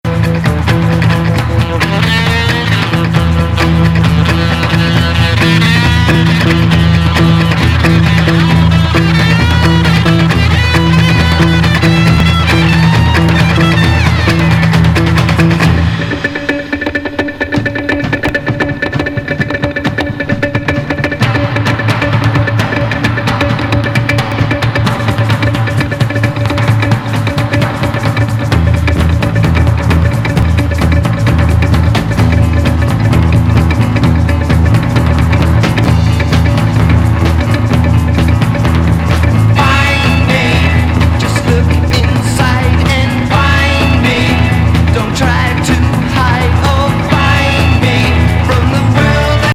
デリックかつぼんやりとしたドローン要素も満載で、ロックのみでは語れない
レフティー質感たっぷりの一枚!